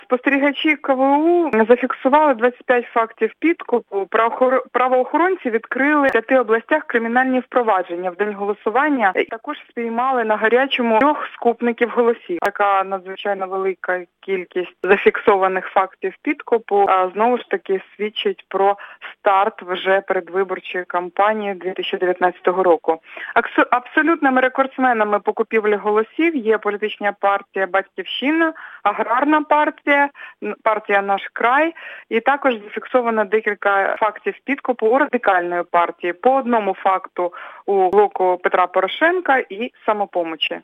Прослухати репортаж Хочеш знати більше - слухай новини на Українському радіо!